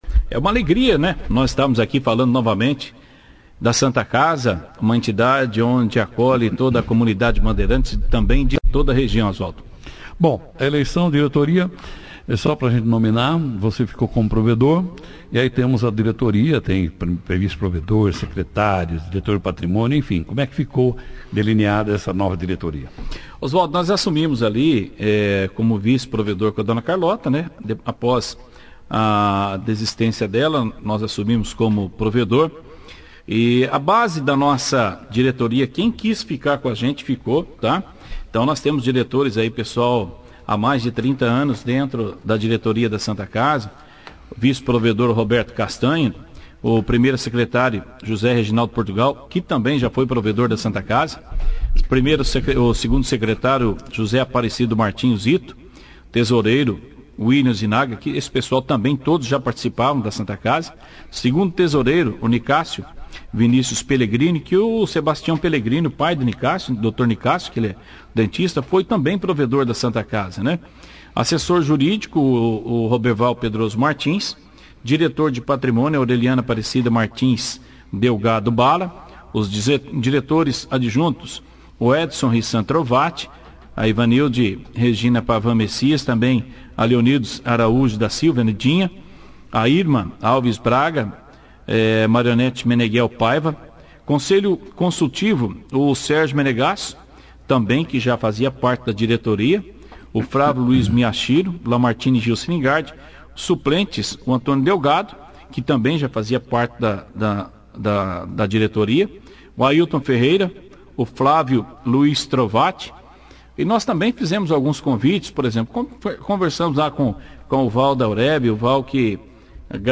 O empresários participou da 2ª edição do jornal Operação Cidade desta terça-feira, 29/12, falando do trabalho que realizou nos 11 meses e do novo compromisso assumido com esta eleição para estar a frente da entidade por mais 3 anos.